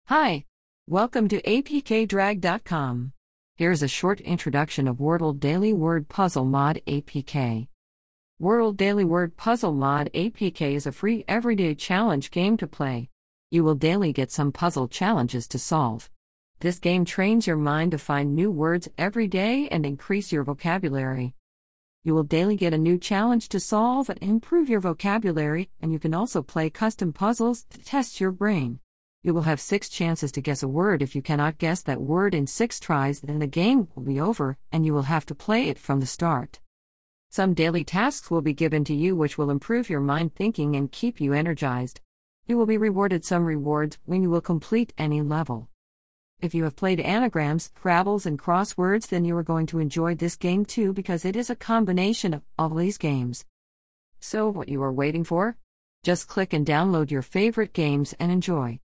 Wordle-Daily Word Puzzle Short Audio Introduction